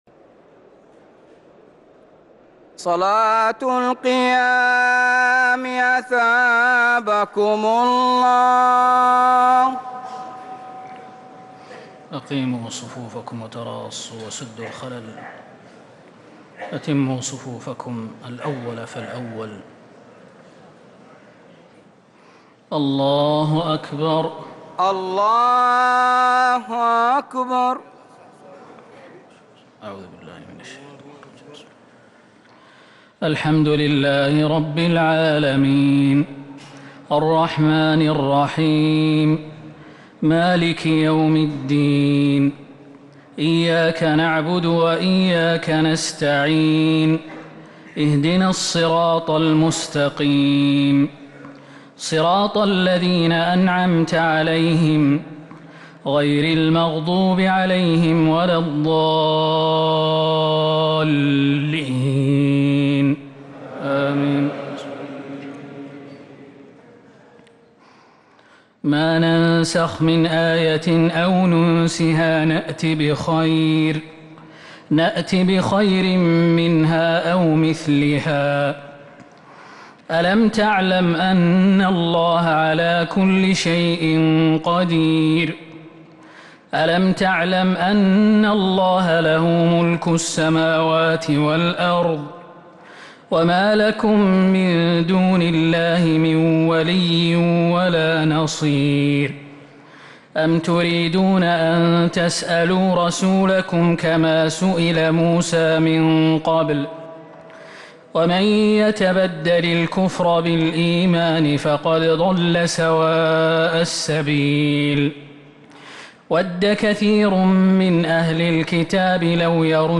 تراويح ليلة 2 رمضان 1444هـ من سورة البقرة (106-167) | Taraweeh 2 st night Ramadan 1444H > تراويح الحرم النبوي عام 1444 🕌 > التراويح - تلاوات الحرمين